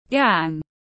Băng nhóm tiếng anh gọi là gang, phiên âm tiếng anh đọc là /ɡæŋ/.
Để đọc đúng tên tiếng anh của băng nhóm rất đơn giản, các bạn chỉ cần nghe phát âm chuẩn của từ gang rồi nói theo là đọc được ngay.